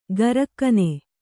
♪ garakkane